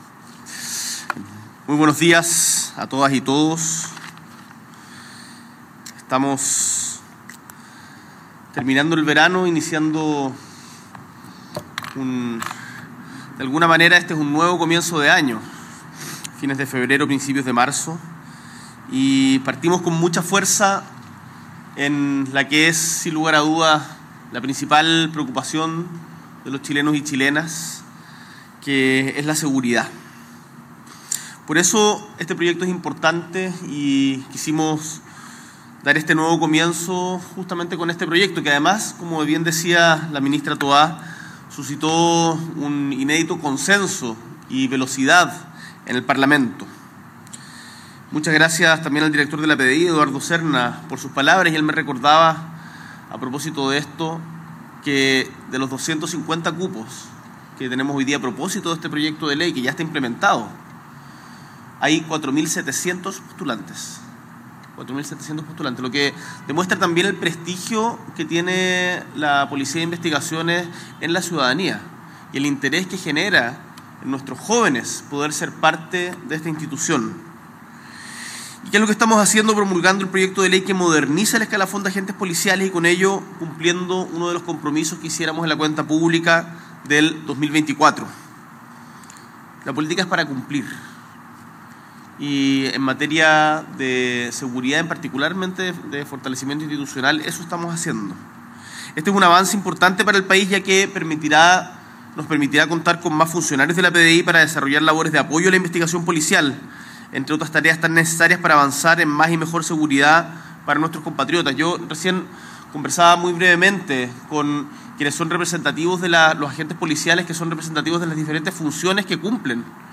S.E. el Presidente de la República, Gabriel Boric Font, encabeza la promulgación de la Ley que Moderniza el Escalafón de los Agentes Policiales de la PDI junto a la ministra del Interior y Seguridad Pública, Carolina Tohá; el ministro de Educación, Nicolás Cataldo; el ministro (s) de Defensa Nacional, Ricardo Montero; el Director General de la PDI, Eduardo Cerna; el General Director de Carabineros, Marcelo Araya; y el alcalde de Estación Central, Felipe Muñoz.